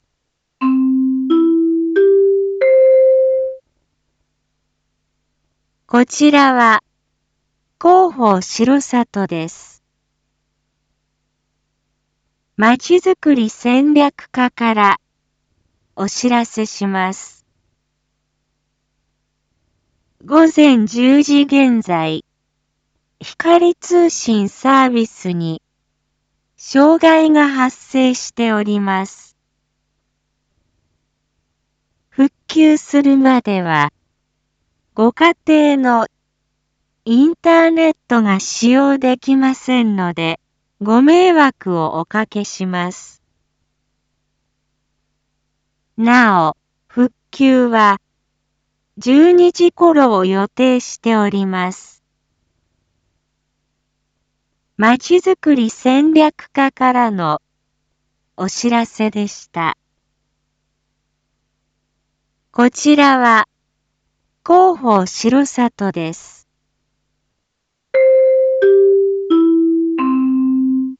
Back Home 一般放送情報 音声放送 再生 一般放送情報 登録日時：2023-01-20 10:26:12 タイトル：七会地区光通信サービス障害発生について（七会地区限定） インフォメーション：こちらは、広報しろさとです。